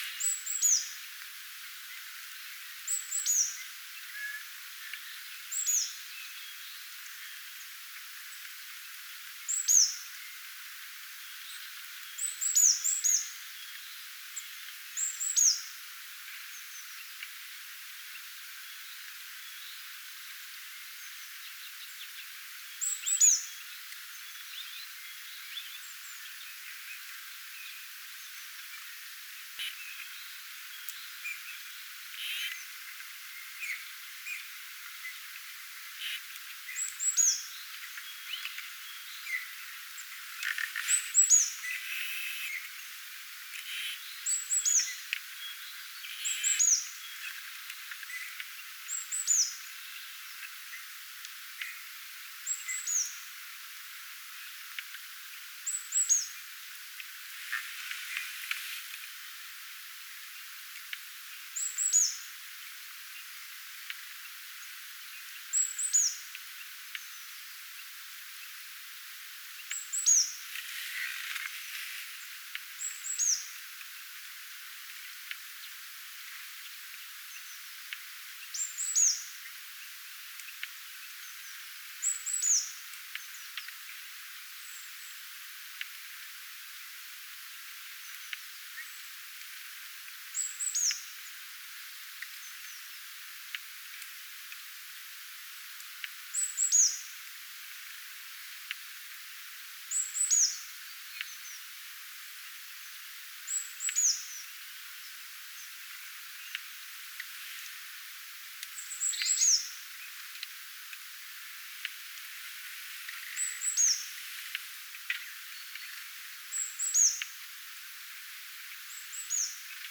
kuusitiainen_laulaa_reipasta_laulua.mp3